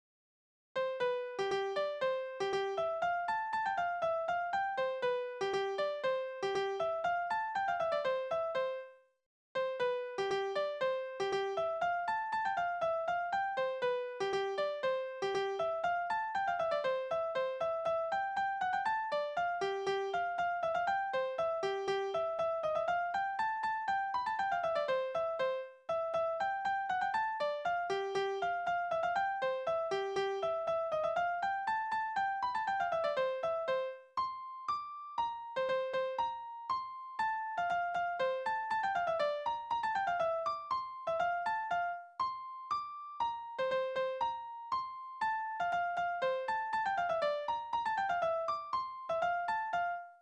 Tanzverse: Dat det hei nich
Tonart: C-Dur, F-Dur
Taktart: 2/4
Tonumfang: Duodezime
Besetzung: vokal
Anmerkung: Vortragsbezeichnung: Kreuzpolka Tonartwechsel von C-Dur nach F-Dur in der Coda/Refrain